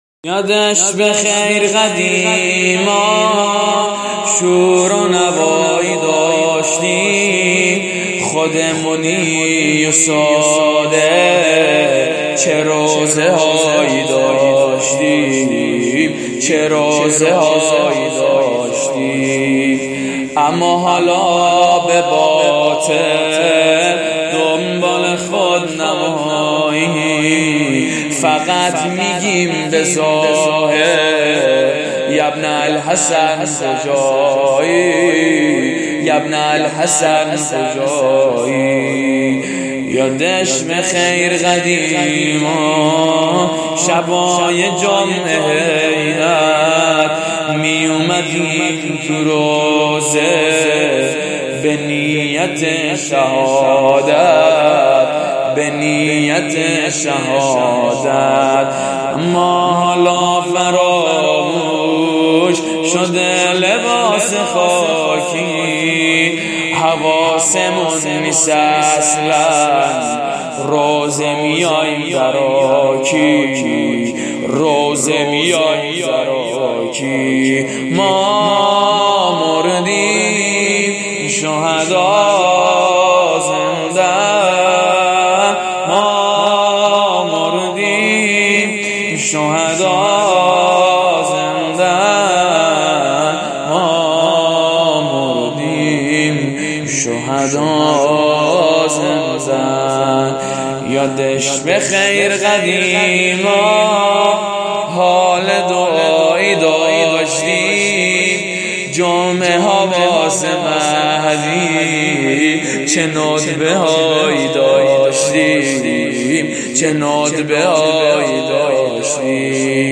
شور قدیمی و ماندگار /یادش بخیر قدیما شور و نوایی داشتیم